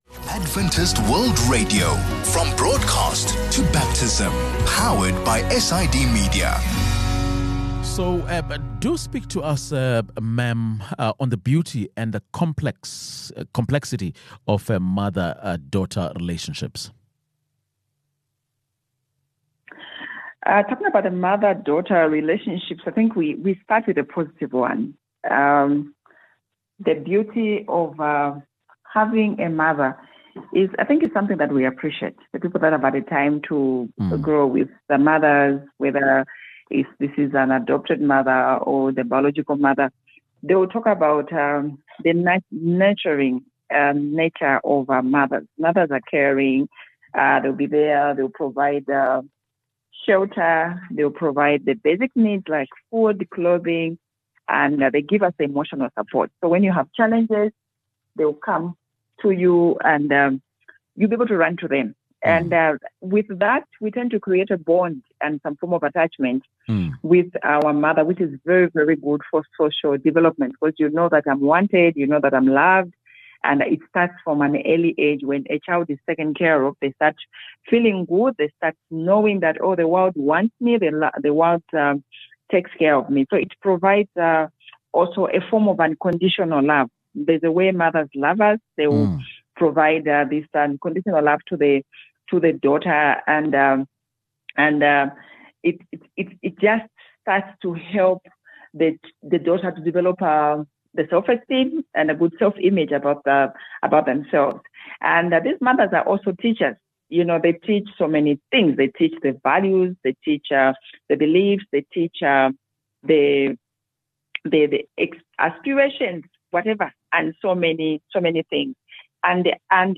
A conversation on the dynamics of the mother-daughter relationship.